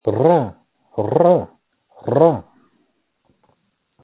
Screams from December 3, 2020
• When you call, we record you making sounds. Hopefully screaming.